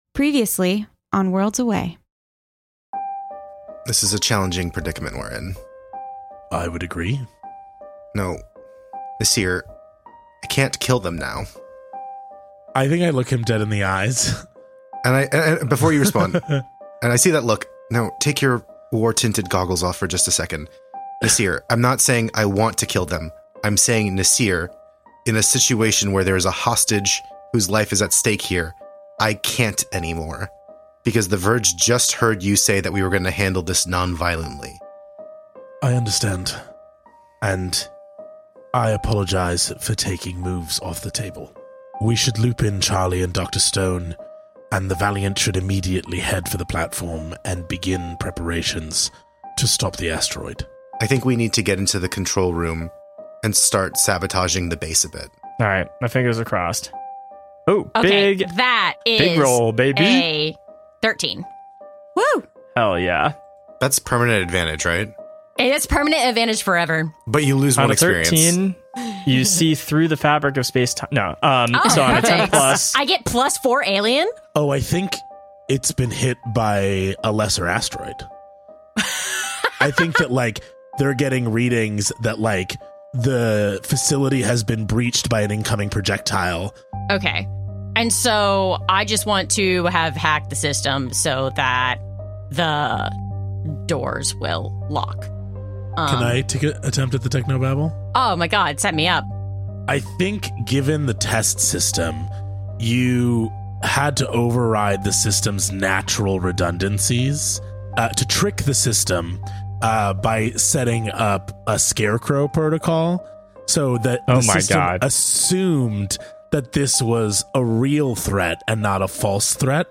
Worlds Away is an actual-play storytelling podcast where five close friends use games to create adventures together as a collective.